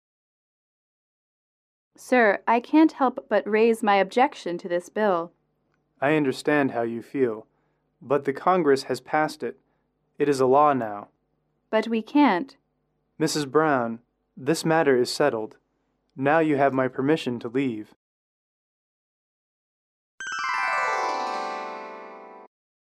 英语主题情景短对话27-2：提案通过审核(MP3)